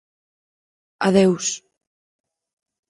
Pronunciado como (IPA)
[aˈðeʊ̯s]